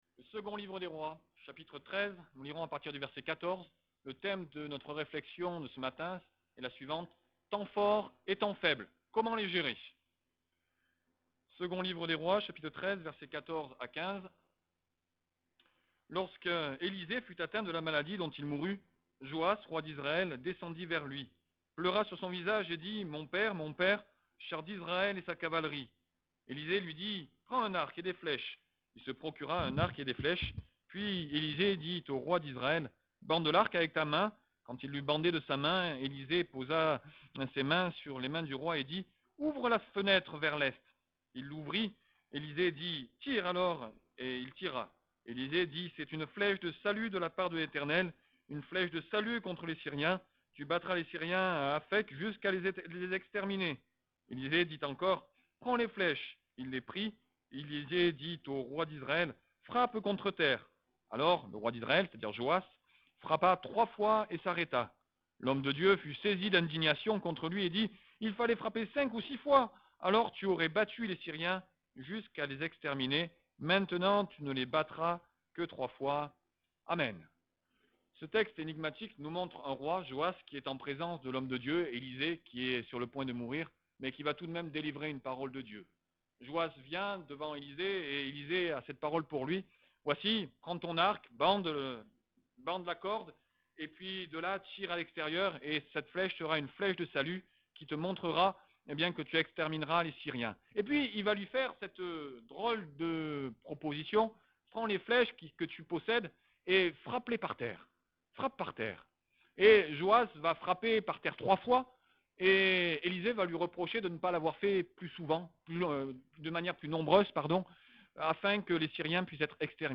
Message audio